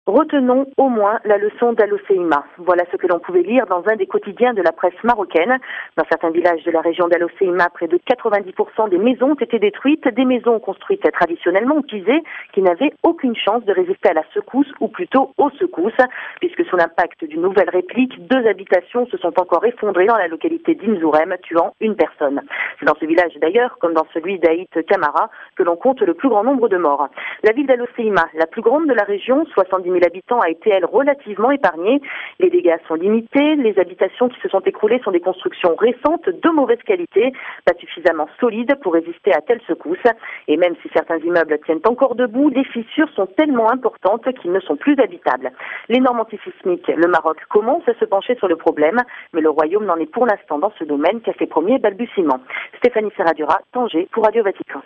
Correspondance